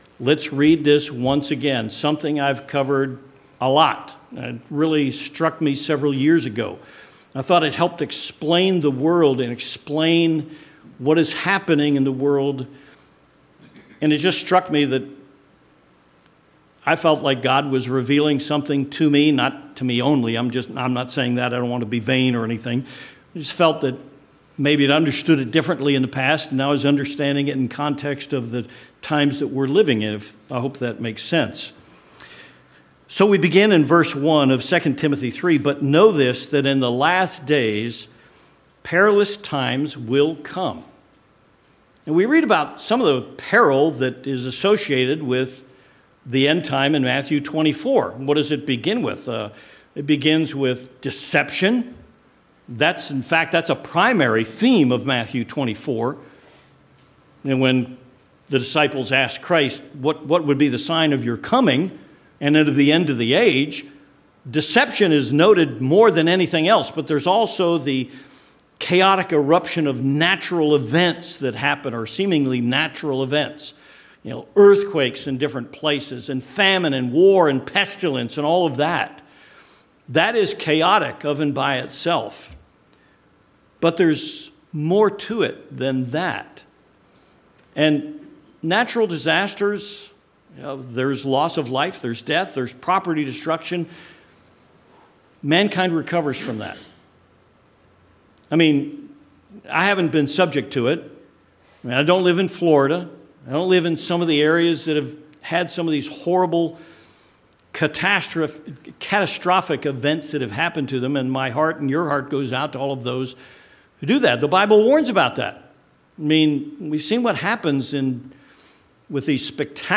This sermon covers several major factors the people of God need for continual awareness.